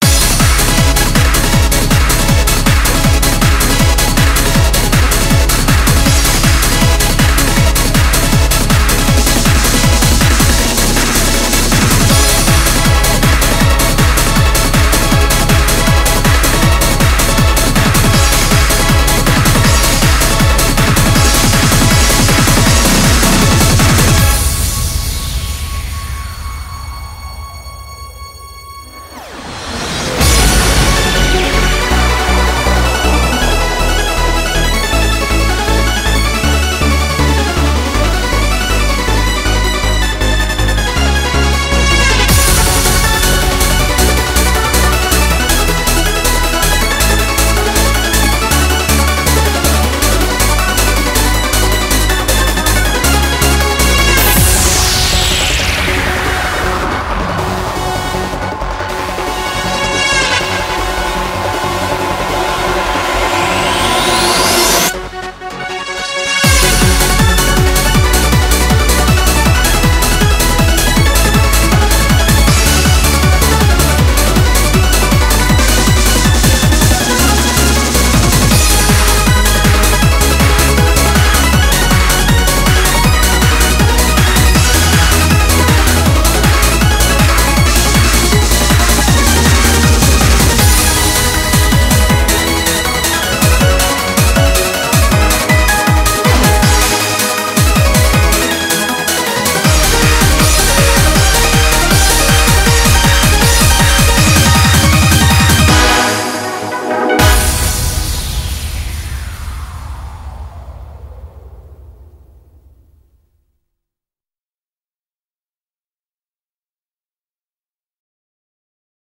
BPM159
Audio QualityPerfect (High Quality)
Commentaires[TRANCE]